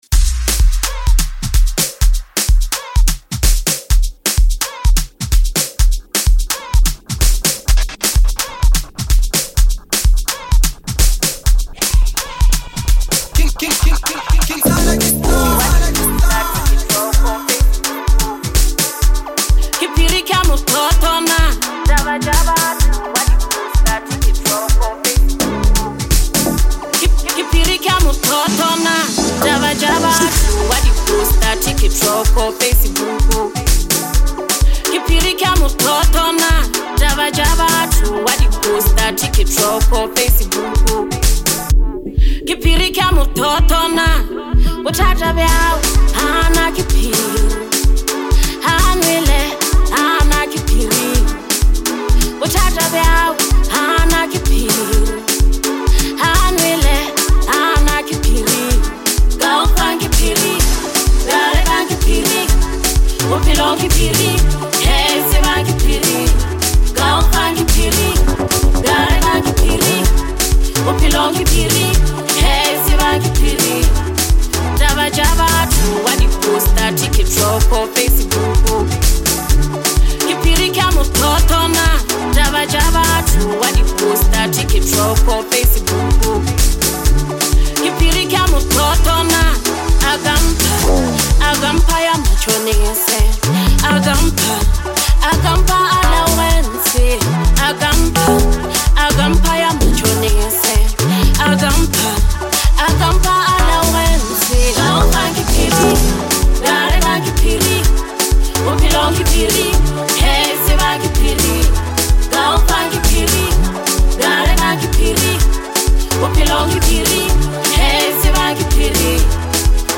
Bolo HouseLekompo